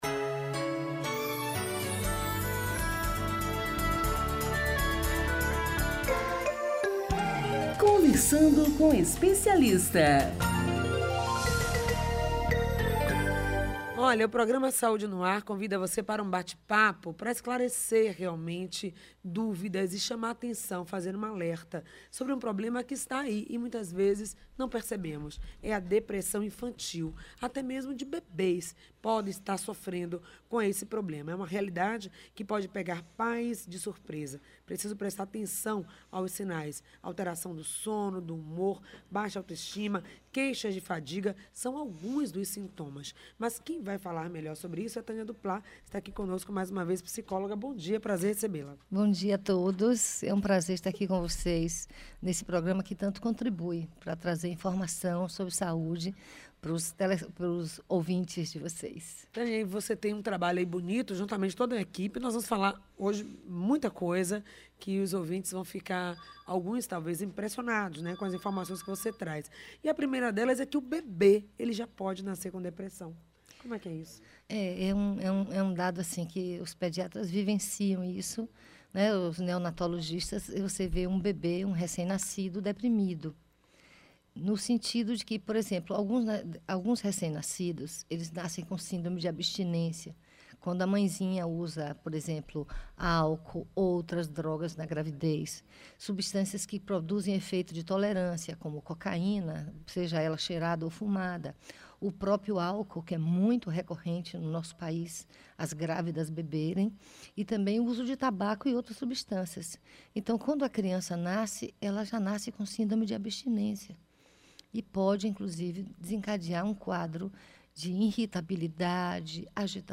O programa exibido pela Rádio AM 840 (em .22.02.16 das 8 às 11h) abordou assuntos como: causas, sintomas, tratamento, onde buscar ajuda